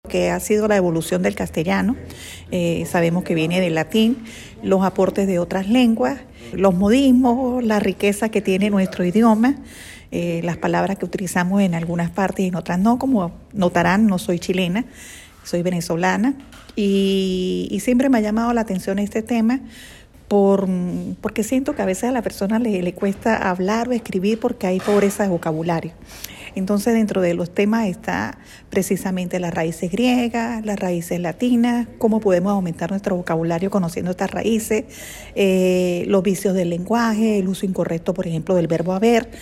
En Biblioteca Central de Osorno se realizó charla sobre evolución del castellano